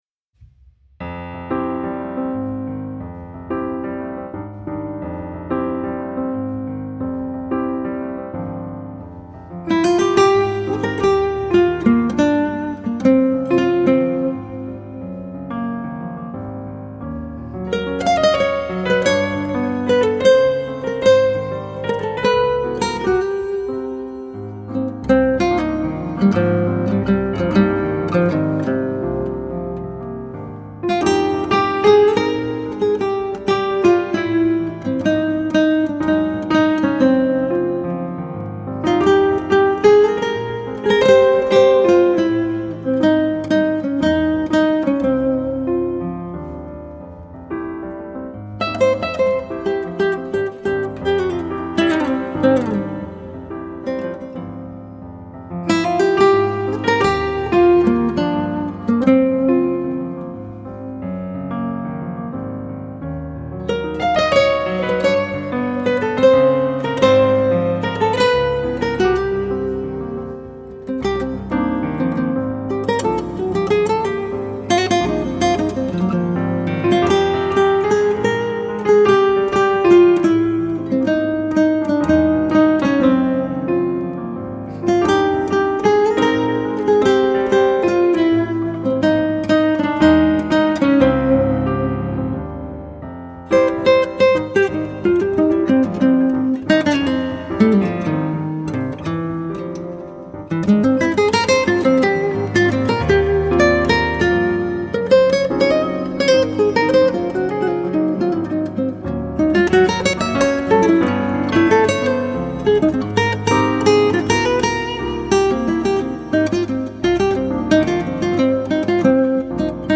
piano/composer
guitar.
It’s a tricky one, mostly because of the insane changes.